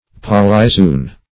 Search Result for " polyzoon" : The Collaborative International Dictionary of English v.0.48: Polyzoon \Pol`y*zo"["o]n\, n.; pl.